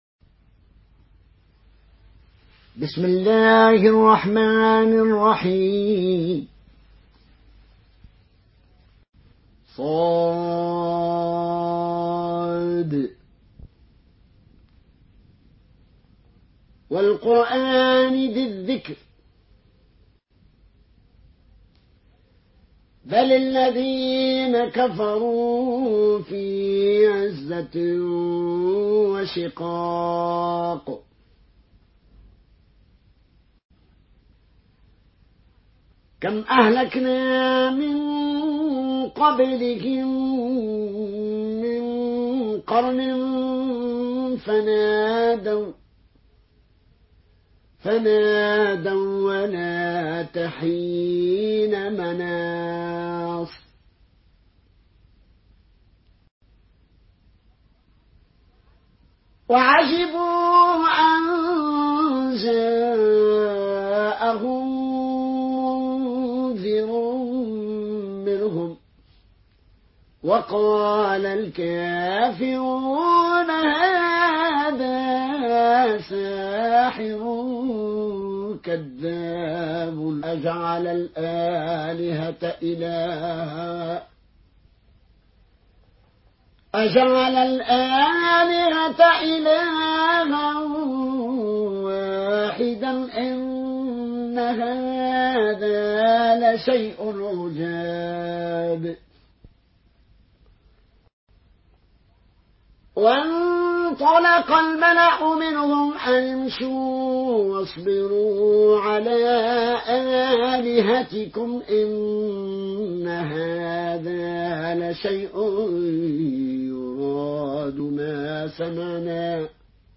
مرتل قالون عن نافع